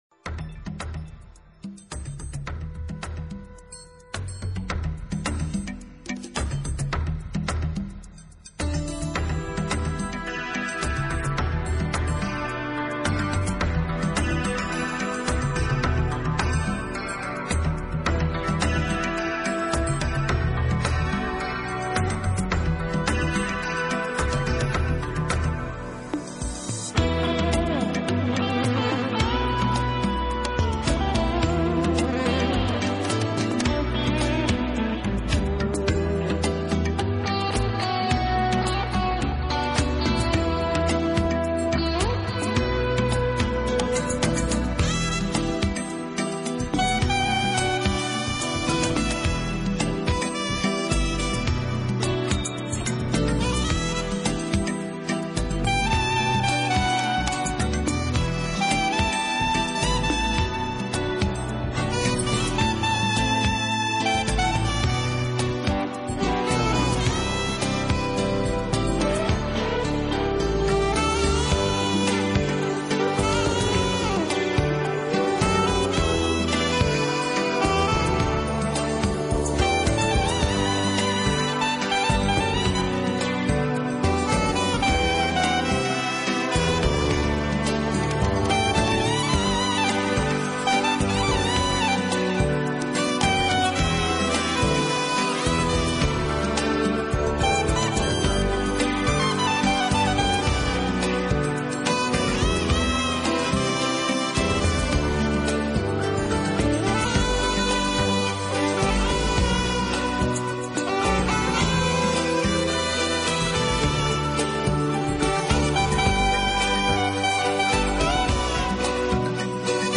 音乐流派：New Age
冥想音乐